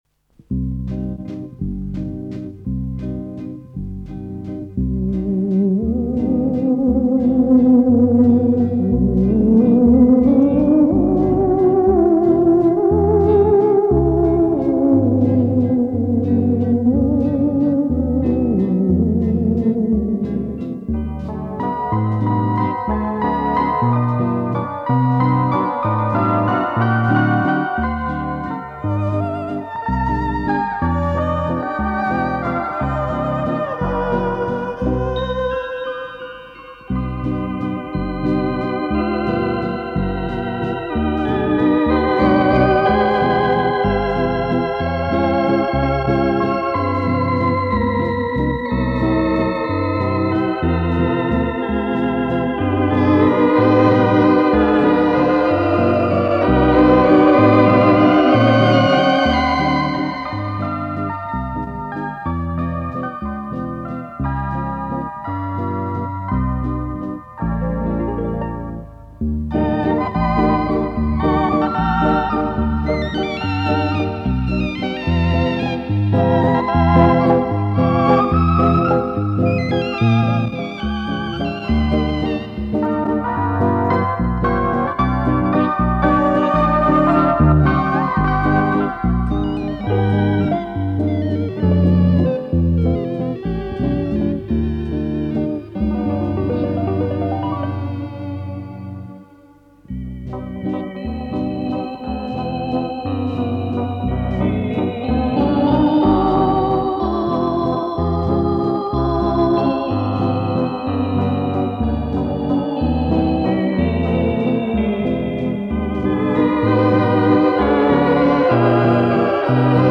вибрафон 4
электроорган